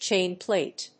意味・対訳 チェーンプレイト